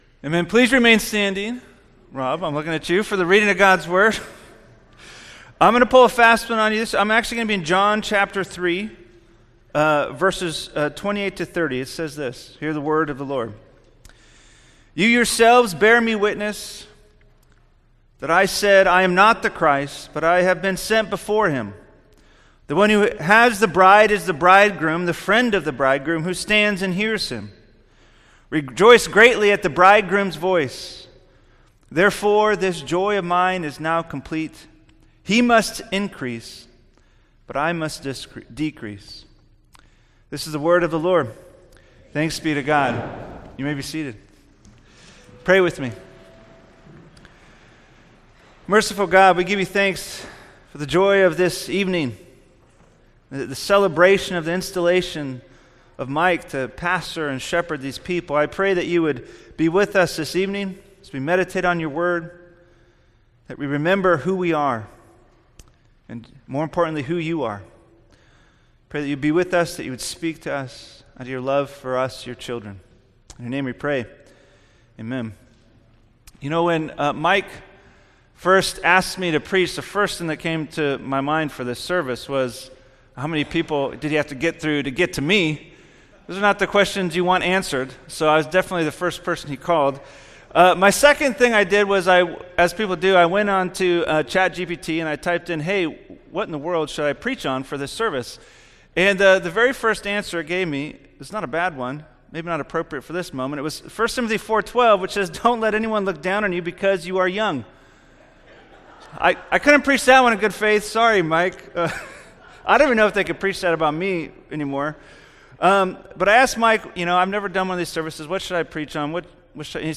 Not the Christ – Senior Pastor Installation Service